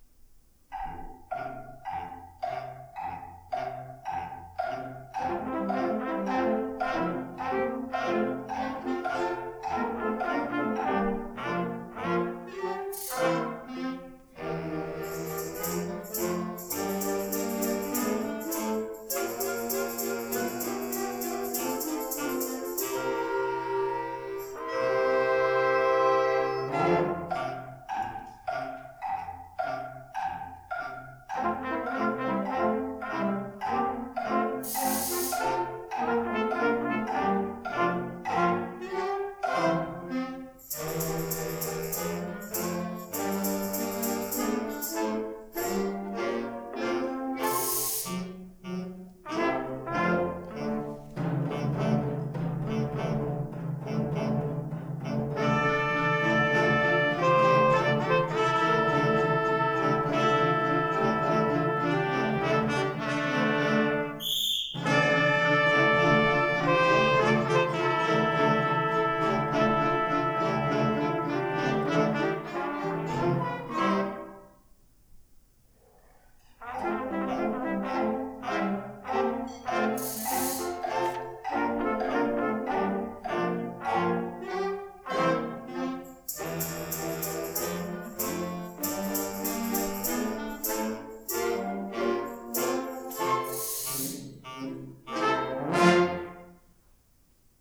9-12 Grade Sr. High Band -